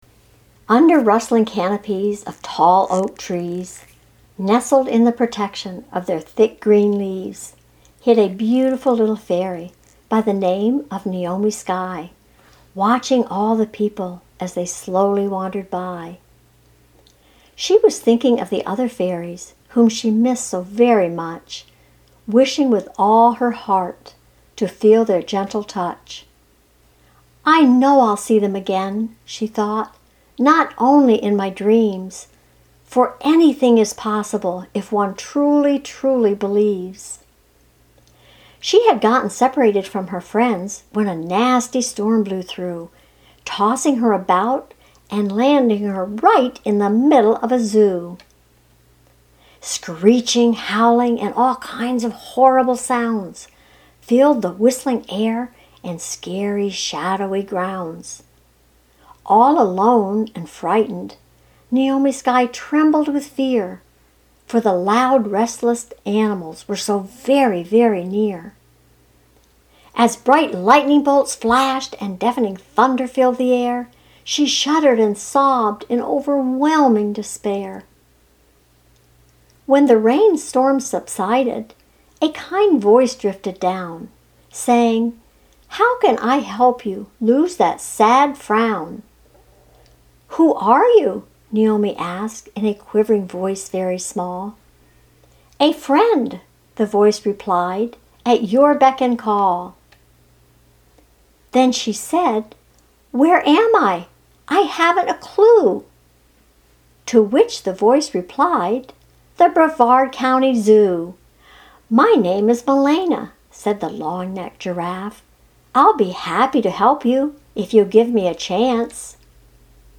Reading A Fairy's Wish